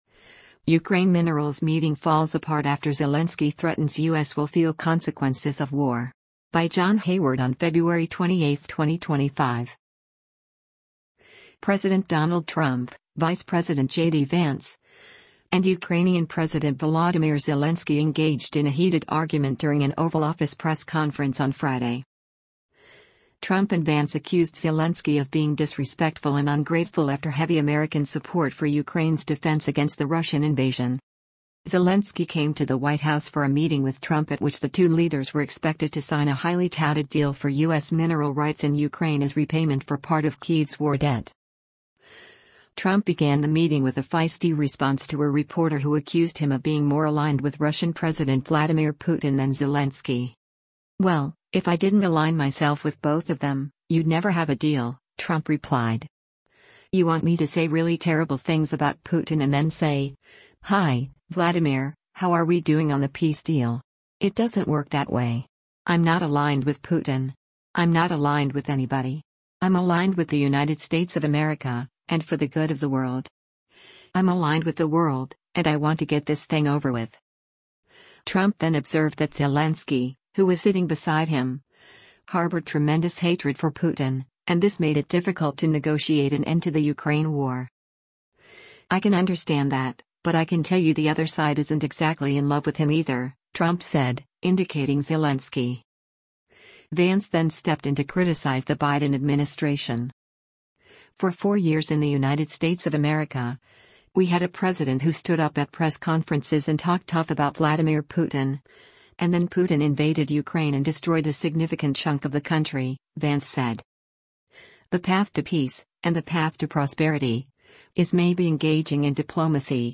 President Donald Trump, Vice President JD Vance, and Ukrainian President Volodymyr Zelensky engaged in a heated argument during an Oval Office press conference on Friday.
Trump and Zelensky talked over each other for a few moments, and then Trump said, “You’re not in a very good position. You’ve allowed yourself in a very bad position … You don’t have the cards right now. With us, you start having cards.”
“We’re not playing cards,” Zelensky interjected angrily.